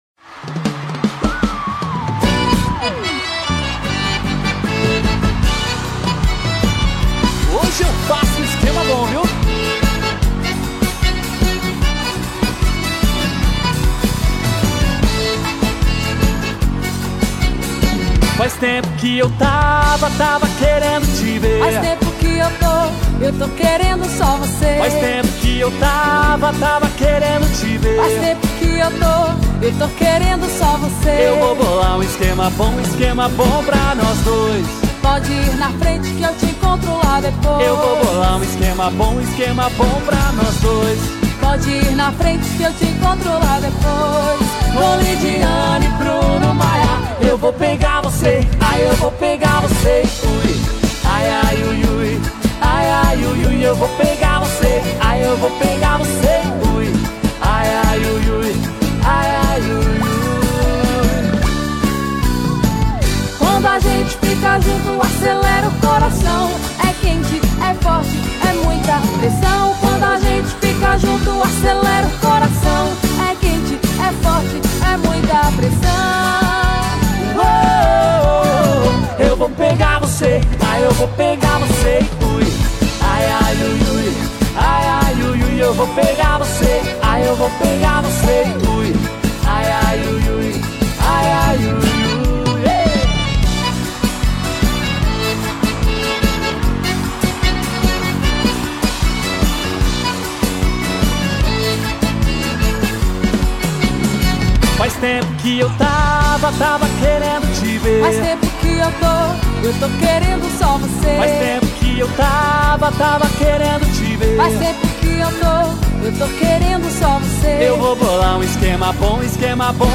Arrocha